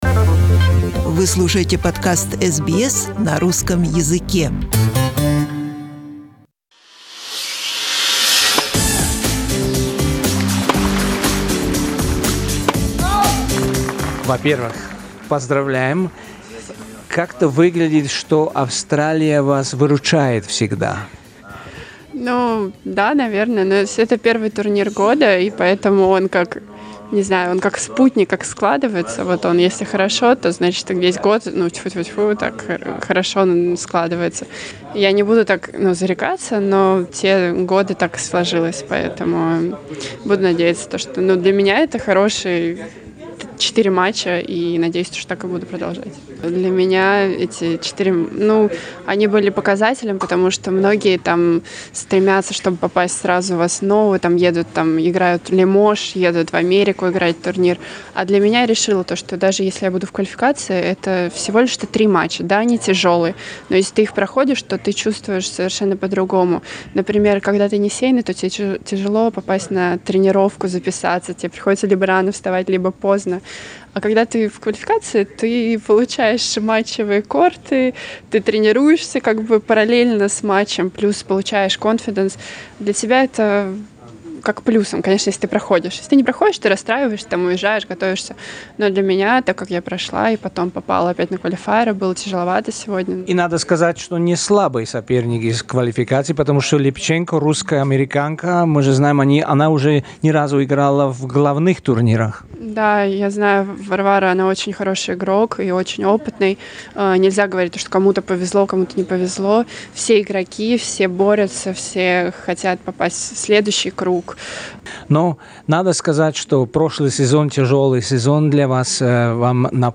Интервью второго дня Открытого чемпионата Австралии